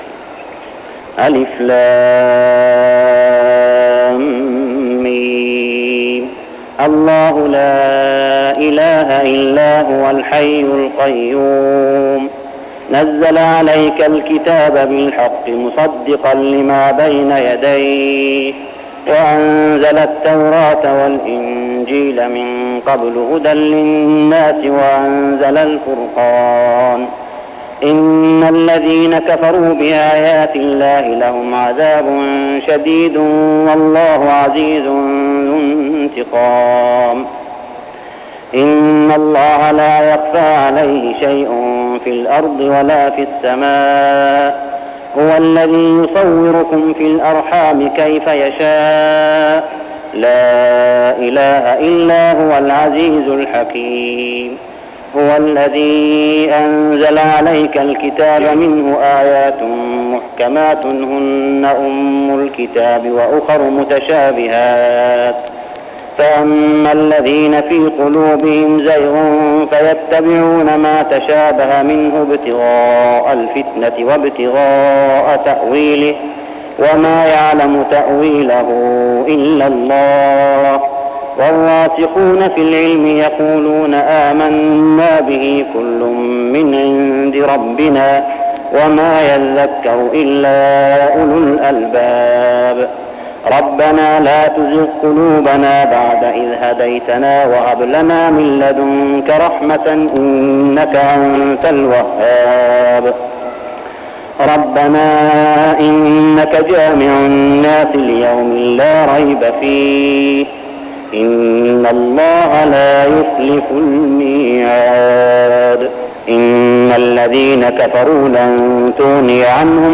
من ليالي رمضان 1405هـ سورة آل عمران 1-32 | From nights of Ramadan Surah Al Imran > تراويح الحرم المكي عام 1405 🕋 > التراويح - تلاوات الحرمين